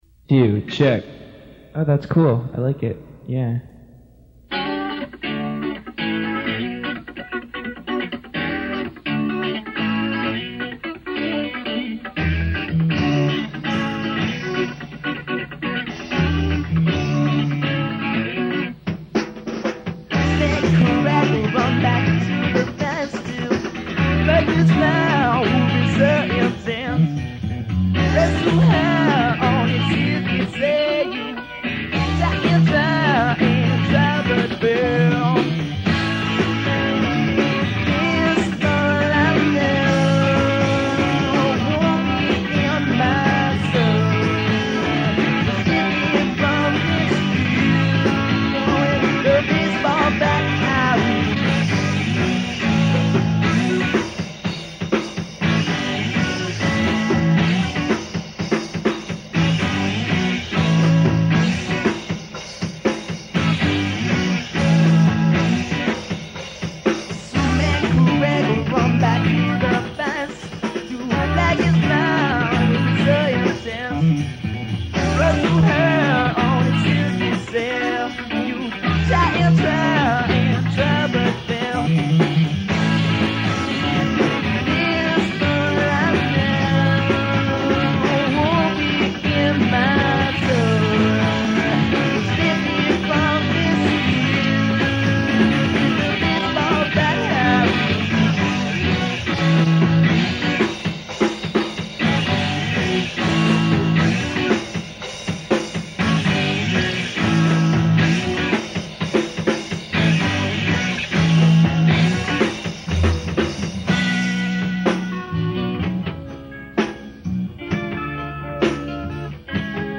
DEMO RECORDINGS